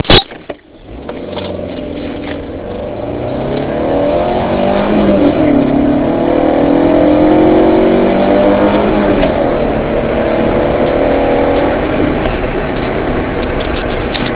En effet, à 4000 tpm, il envoie tout ce qu'il a, l'accélération n'est pas linéaire et ça se ressent bien. Le son est également agréable : ouvrez les fenêtres pour en profiter :D
En bonus, un son tout pourri (enregistré avec mon tel portable) :
Opel_Corsa.wav